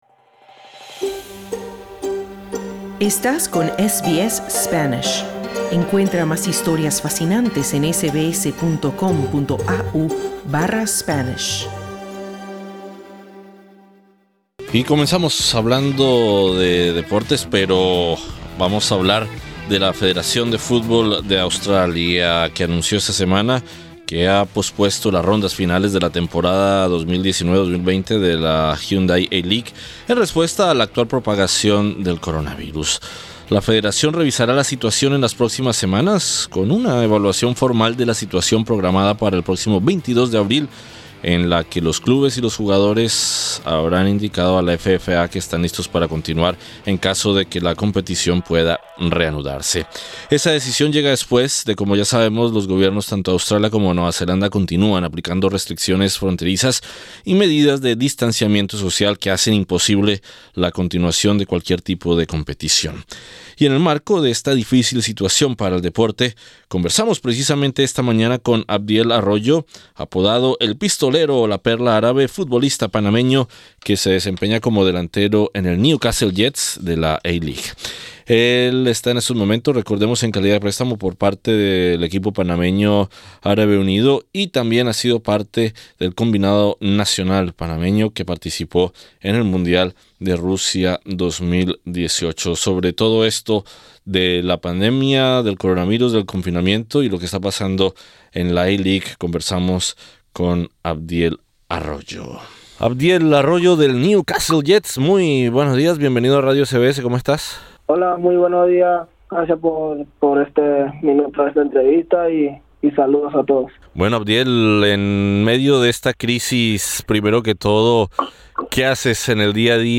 En el marco de esta crisis conversamos con el delantero del Newcastle Jets, el panameño Abdiel Arroyo, quien nos cuenta sobre su situación en los tiempos del coronavirus.